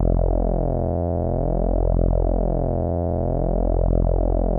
SMOOTH RES 1.wav